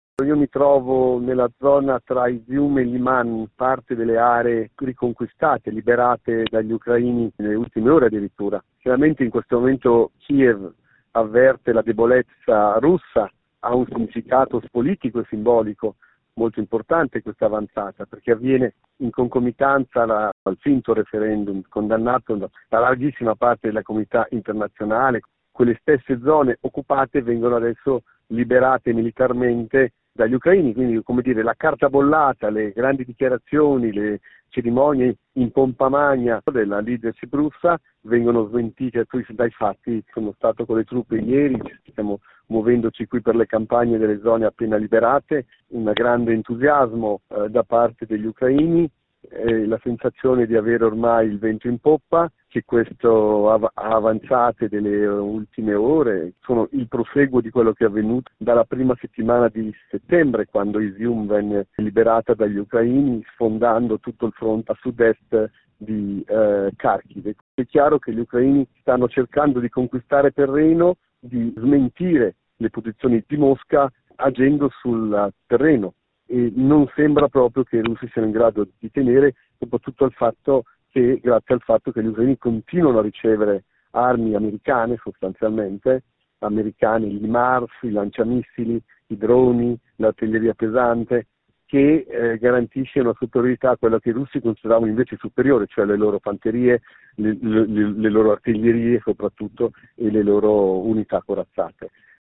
Il racconto della giornata di domenica 2 ottobre 2022 con le notizie principali del giornale radio delle 19.30. In Ucraina i russi concentrano i proprio attacchi sulla regione di Kharkhiv e intorno a Zaporizha, ma l’avanzata dell’esercito di Kiev nel sud-est del Paese sembra inarrestabile.